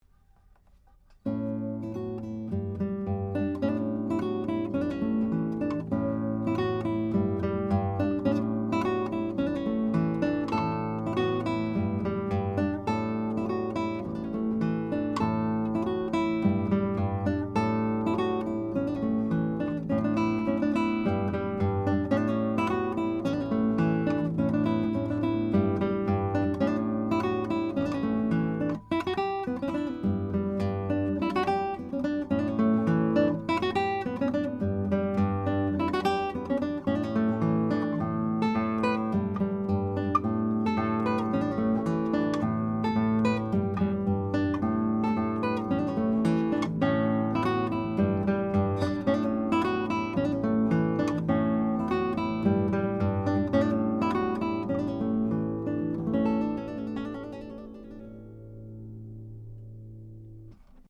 Here are 14 MP3s of the Chandler TG Channel using a Neumann TLM67 on Milagro 10-String Classical Harp Guitar into a Metric Halo ULN-8 converter, to Logic, with no additional EQ or any other effects: